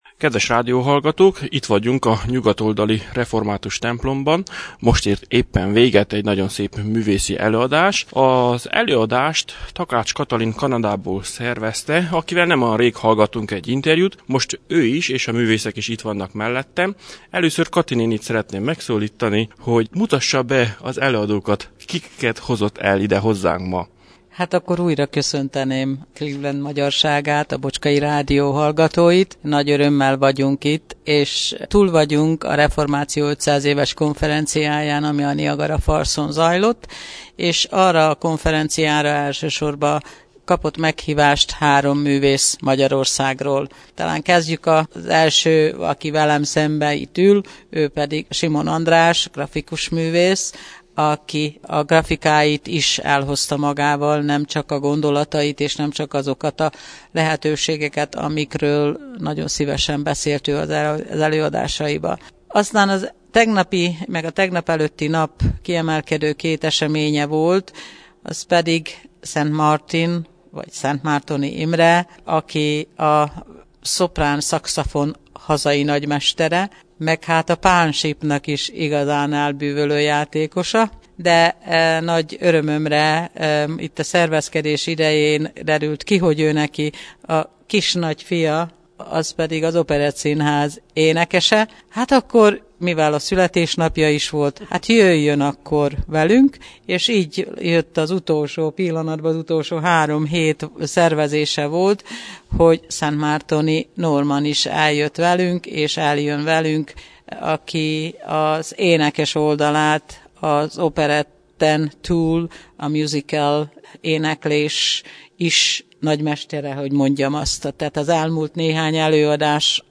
Június 9-én pénteken a Nyugat-oldali Református Templomban egy igazán szép művész estre került sor.
a sopránszaxofon hazai mestere lépett ki az egybegyült clevelandi magyarok elé.
St. Martin a továbbiakban bemutatta másik kedvenc hangszerét a pánsípot.
A versek után a csángó földön jól ismert tilinkó furulyával fújt el egy népdalt.
hogy operett énekes.